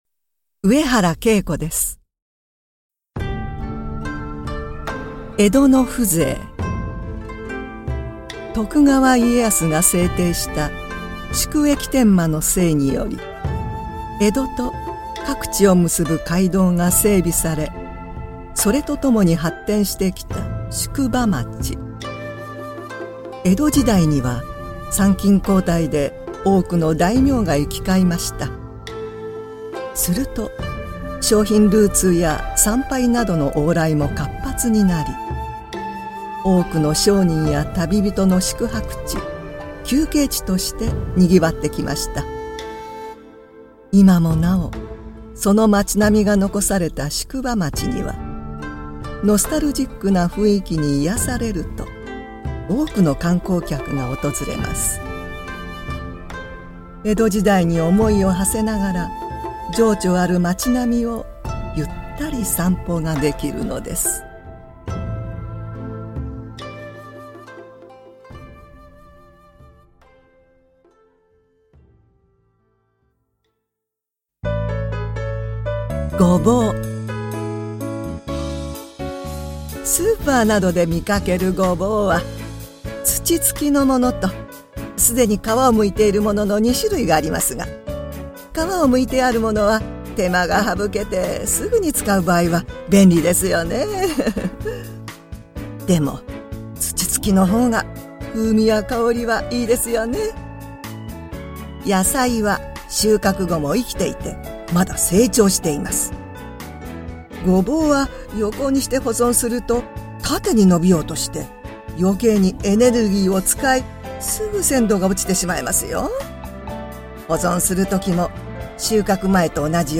語りべ的な、味のある声